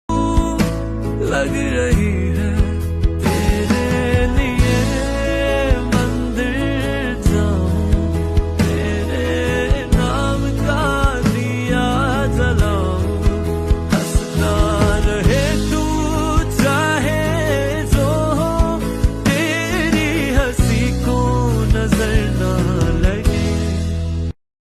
Punjabi ringtone